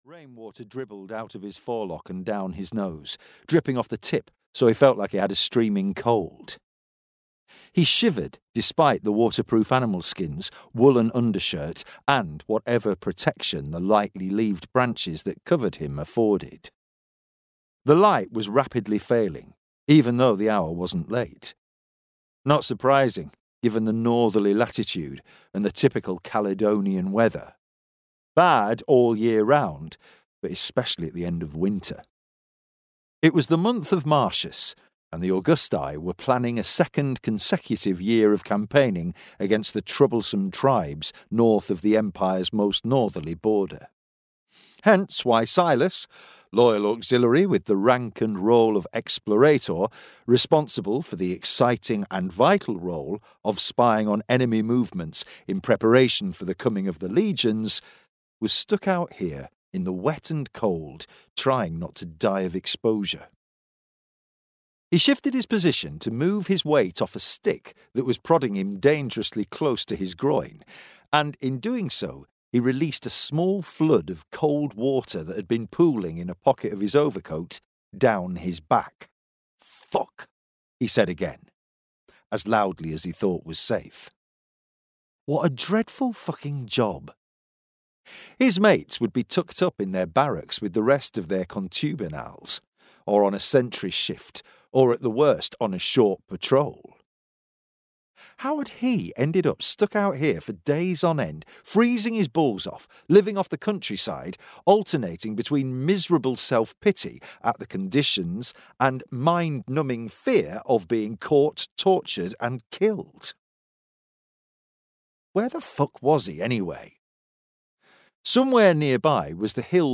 Emperor's Sword (EN) audiokniha
Ukázka z knihy